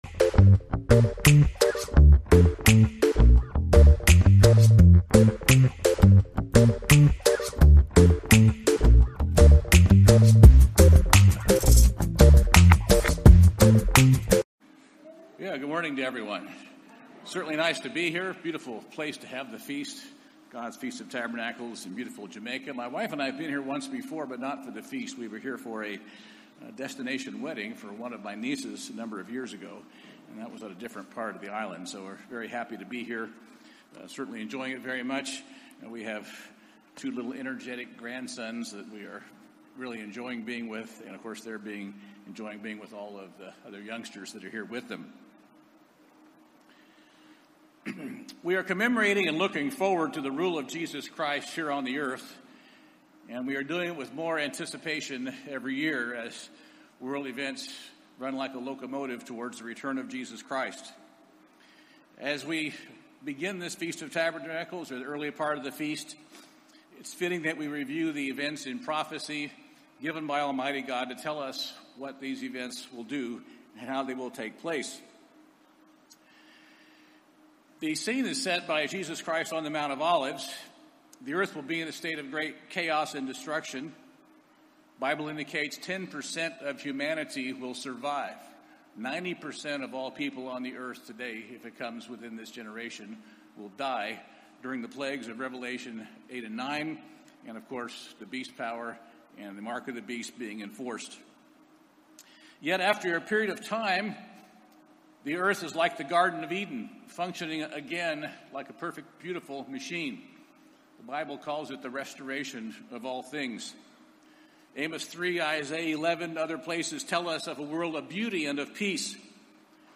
This sermon was given at the Montego Bay, Jamaica 2021 Feast site.